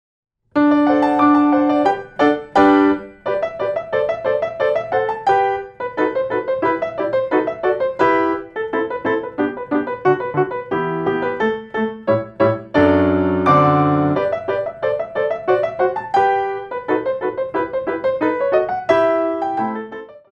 4 bar intro 2/4